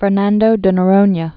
(fər-năndō də nə-rōnyə, fĕr-näɴd də nô-rônyə)